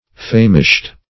famished.mp3